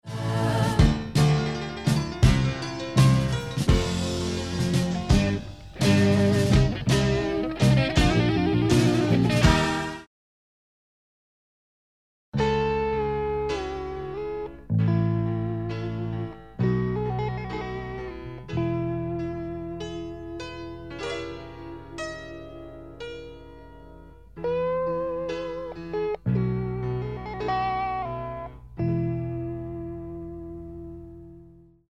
primero en piano y luego en la guitarra